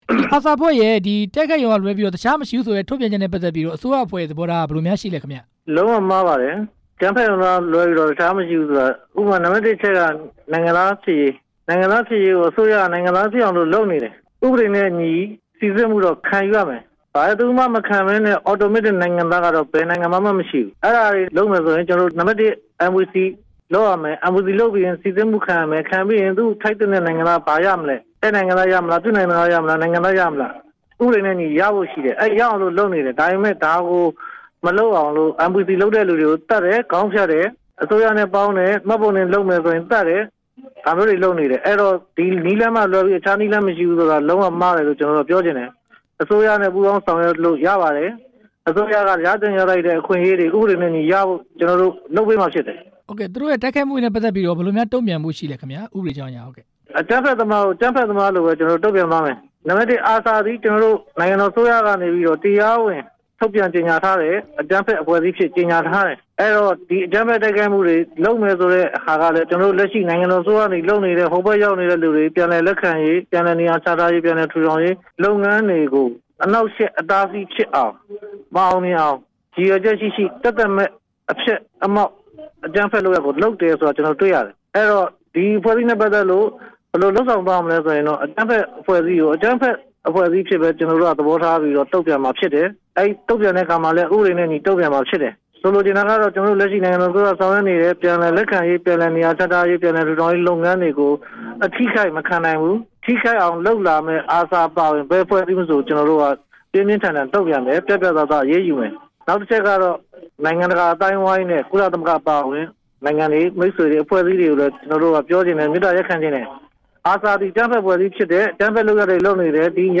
နိုင်ငံတော်အတိုင်ပင်ခံရုံး ညွှန်ကြားရေးမှူးချုပ် ဦးဇော်ဌေးနဲ့ ဆက်သွယ်မေးမြန်းချက်